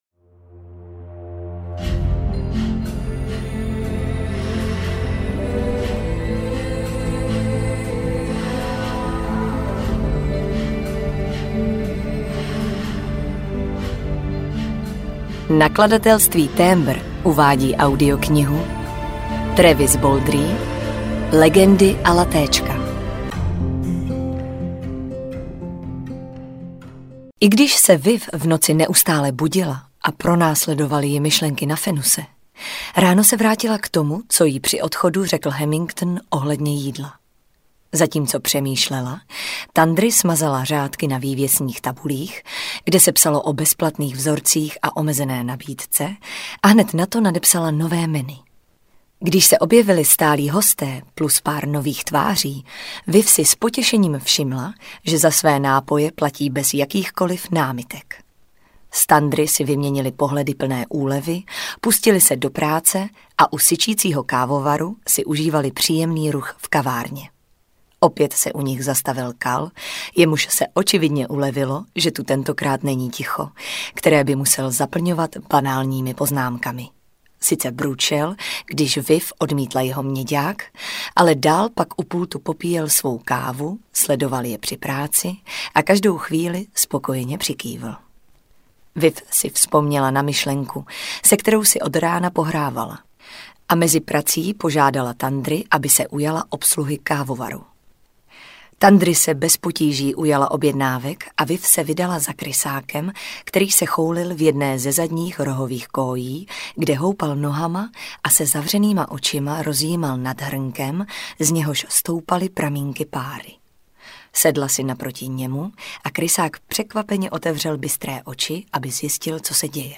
Legendy a latéčka audiokniha
Ukázka z knihy